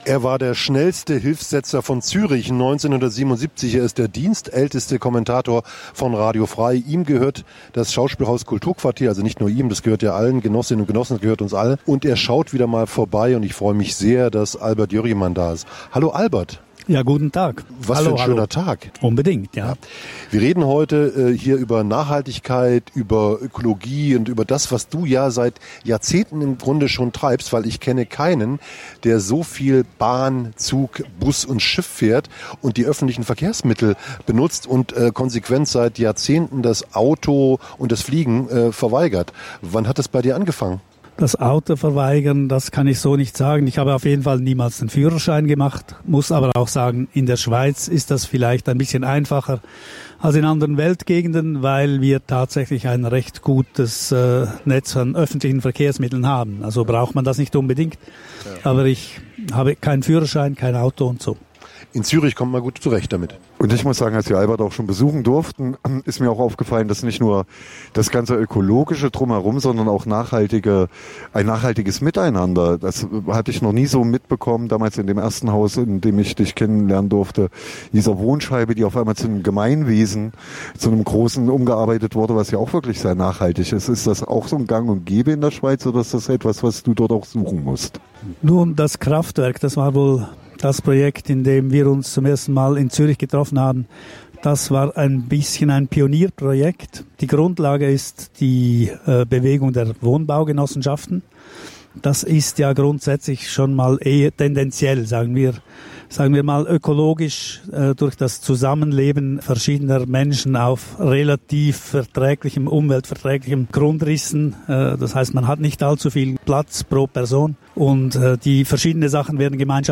Eine Lesung mit Samthandschuhen.